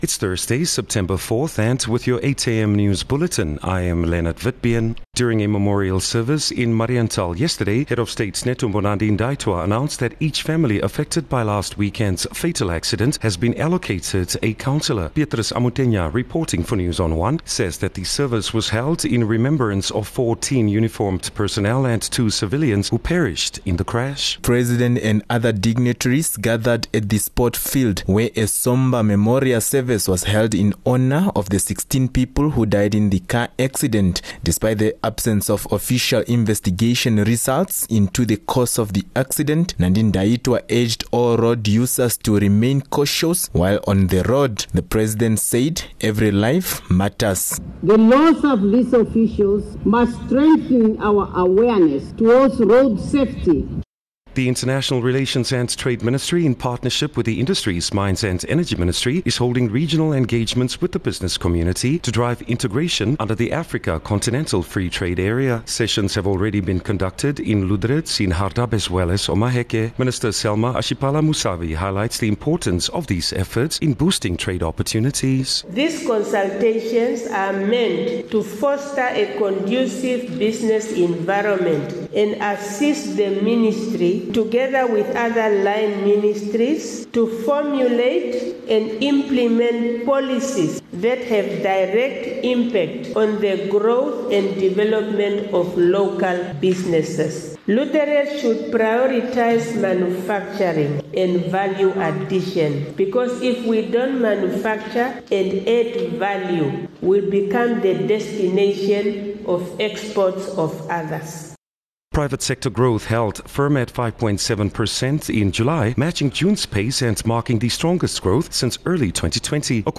5 Sep 4 September-8am news